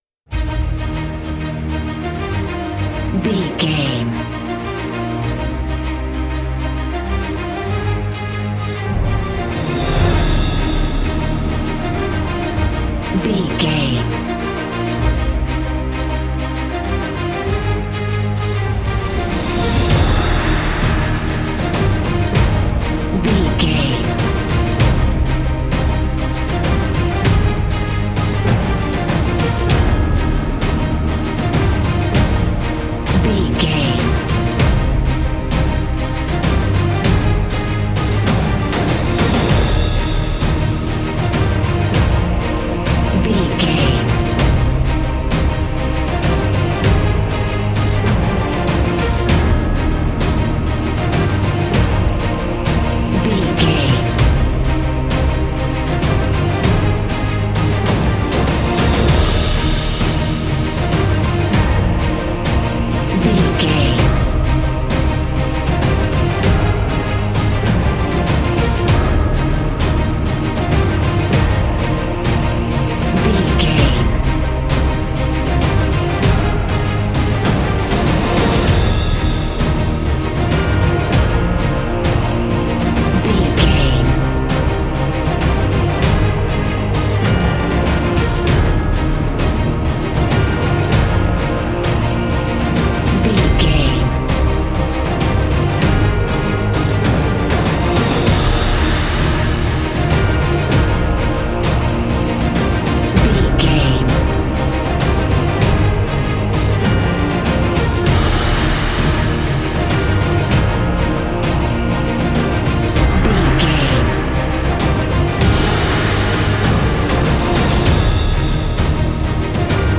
Ionian/Major
E♭
cinematic
energetic
brass
drums
strings